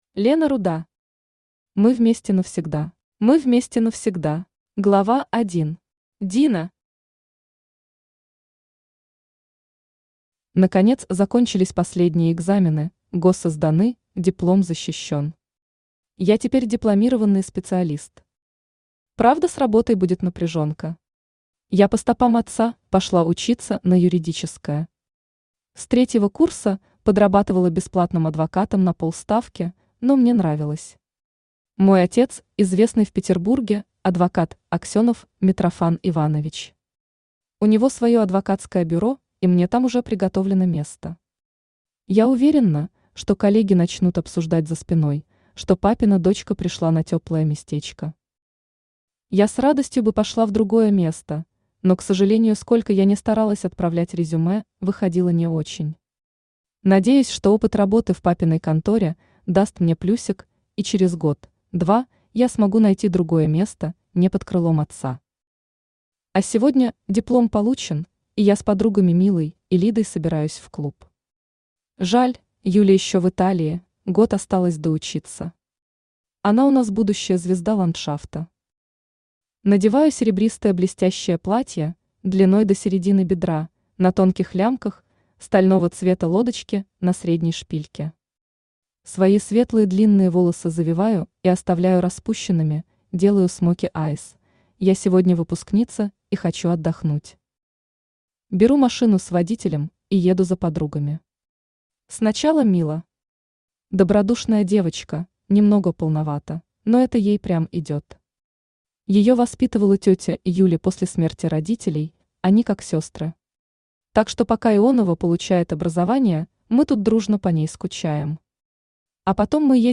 Aудиокнига Мы вместе навсегда Автор Лена Руда Читает аудиокнигу Авточтец ЛитРес.